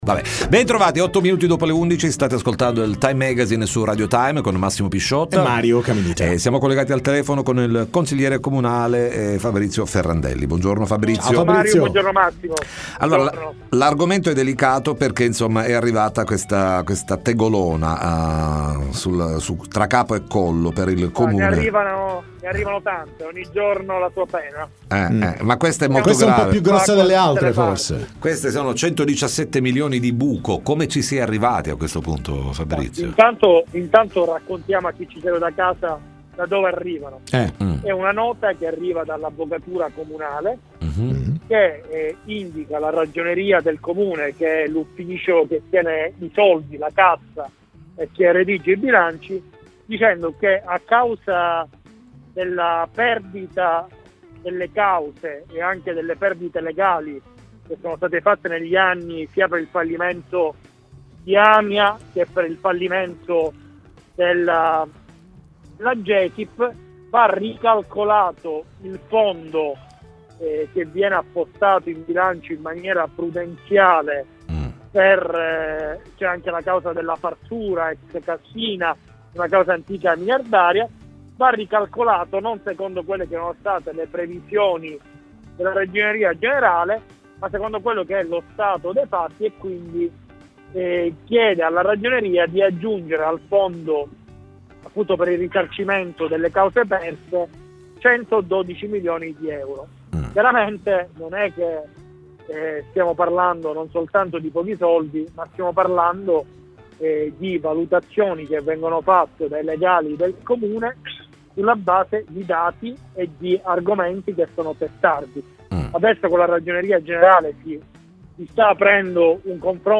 TM intervista Fabrizio Ferrandelli